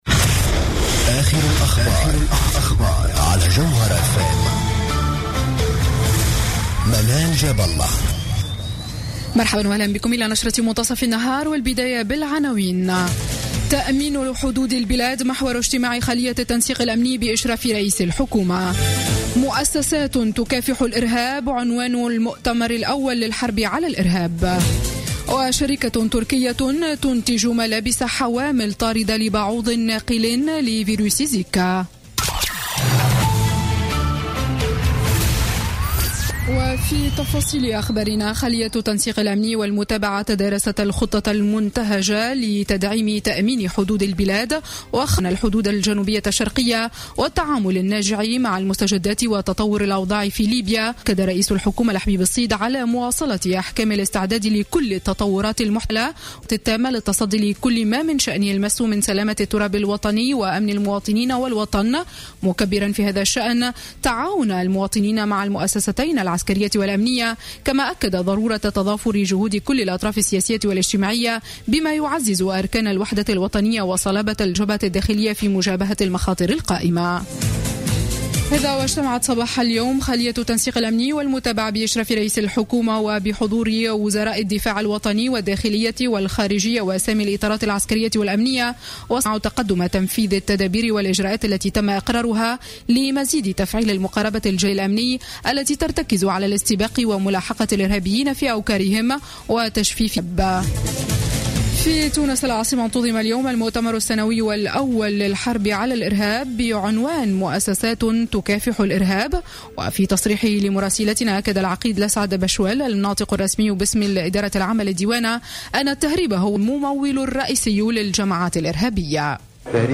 نشرة أخبار منتصف النهار ليوم السبت 5 مارس 2016